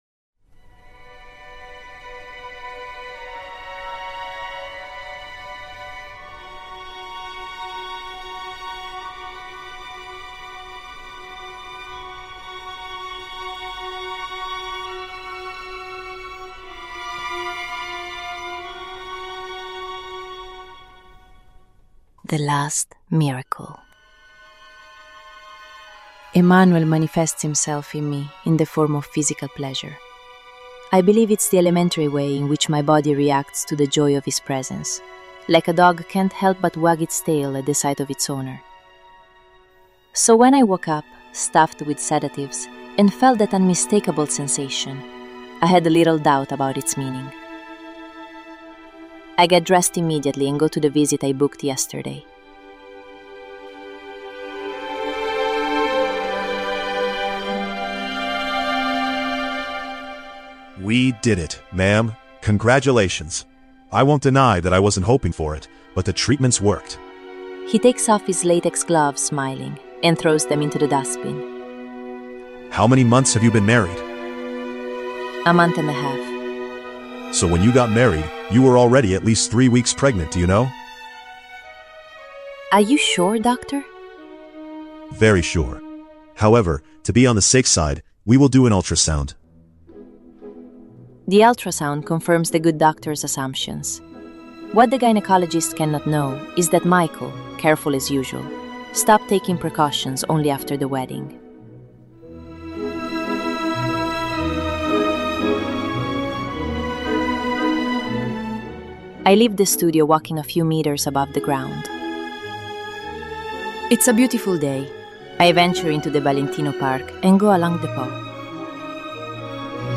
The soundtrack consists of the Overture to Giuseppe Verdi's "Traviata" and the Ballade n. 1 op. 23 in G minor by Chopin.